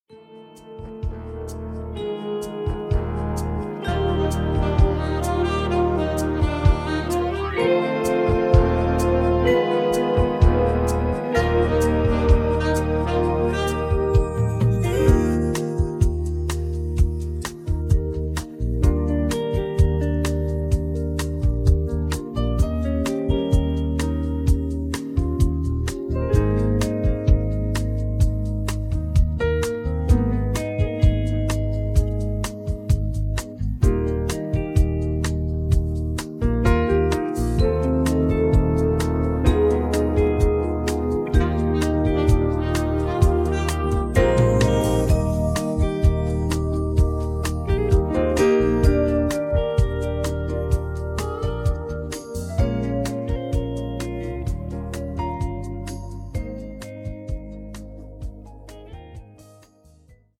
음정 -1키 3:17
장르 가요 구분 Voice Cut